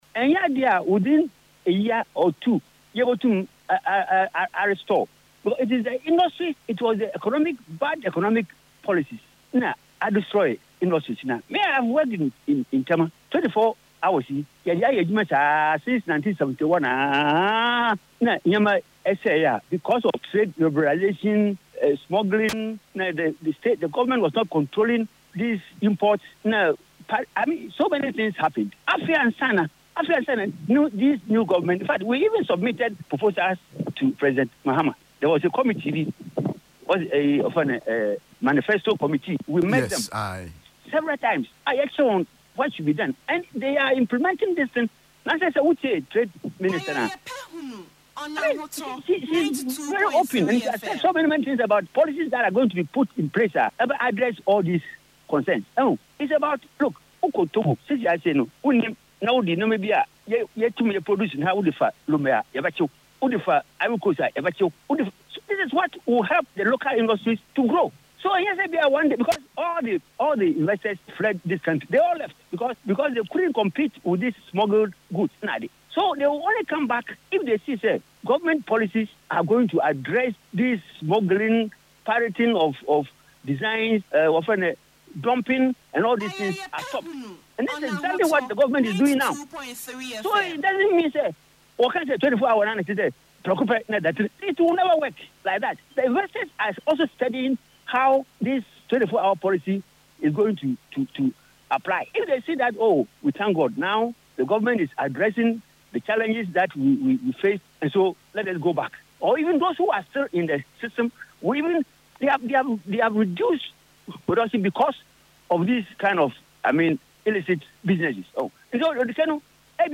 Economy News Politics